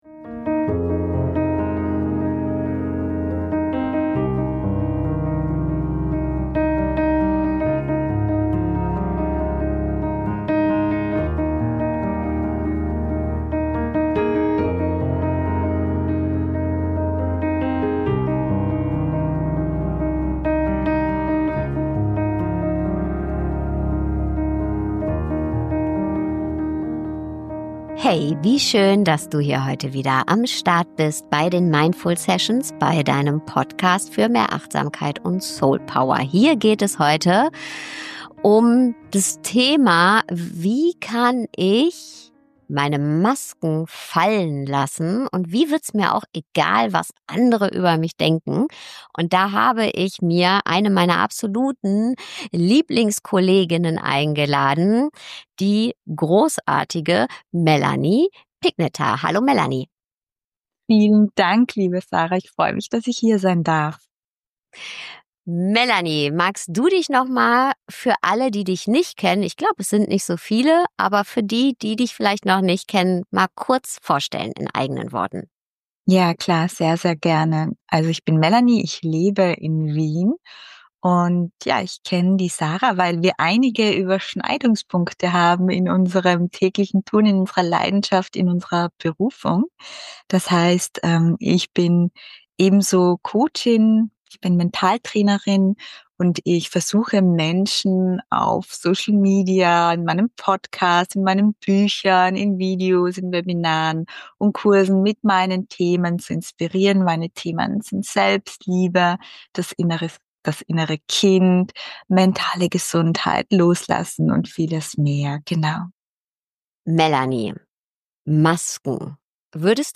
1 Der Mensch hinter der Maske - Interview